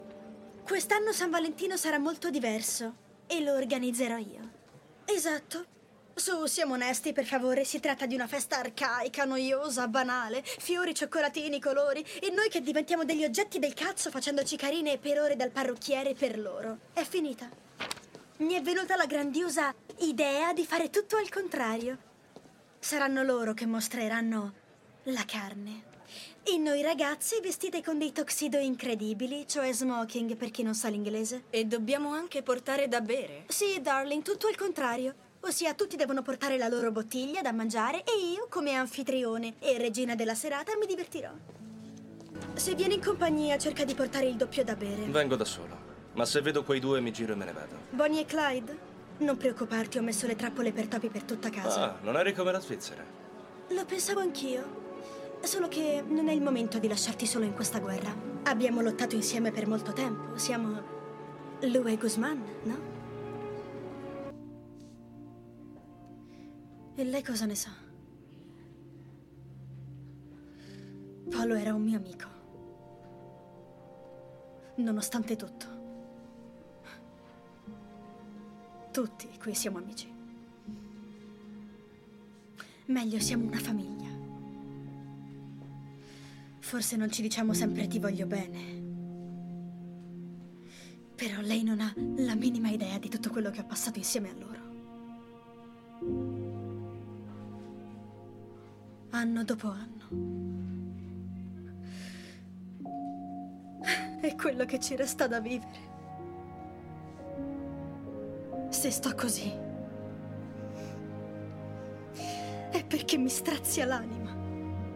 nel telefilm "Elite", in cui doppia Danna Paola.